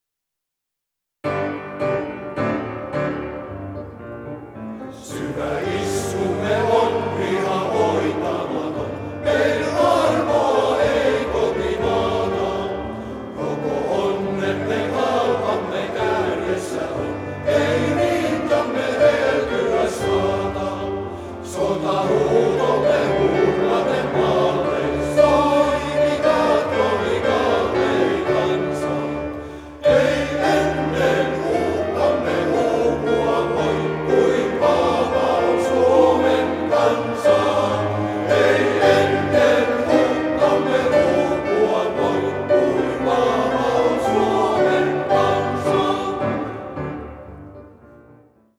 Kuoron lauluohjelmisto koostuu mieskuoro-ohjelmiston lisäksi myös erilaisista ja erimaalaisista sotilaslauluista , hengellisistä lauluista ja seranadeista.
Isänmaallisia lauluja